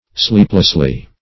Sleepless \Sleep"less\, a.